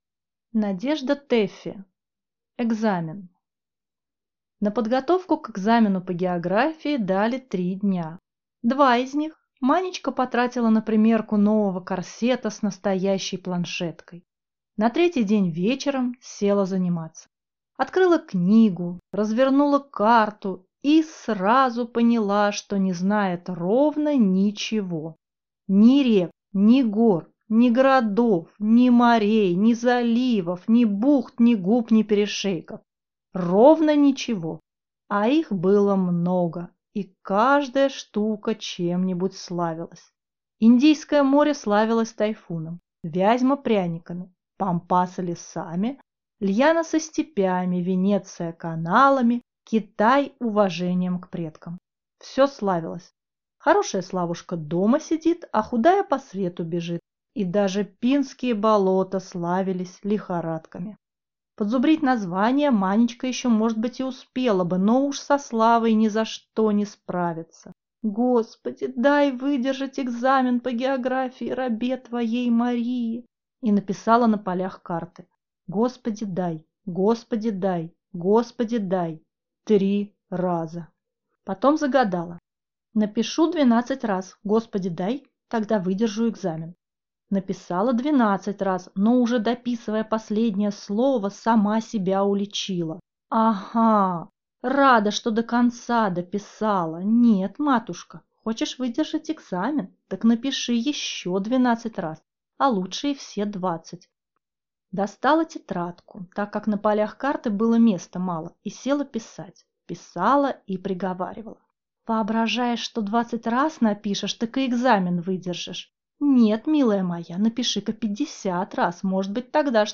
Аудиокнига Экзамен | Библиотека аудиокниг